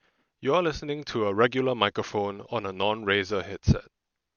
Razer Yellow Linear Mechanical Switch Gen-3
All typing test sound clips are recorded on a stock Razer BlackWidow V4 Tenkeyless HyperSpeed with no modifications.